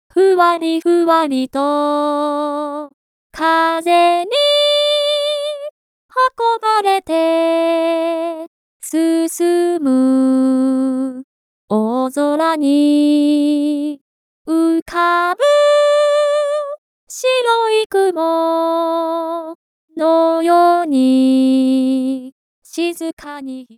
ですので、DOMINO調声法は簡易的ですが「人間の歌声のデータをパターン化して、シミュレートしたもの。」になります。
具体的には、下記のような歌声になります。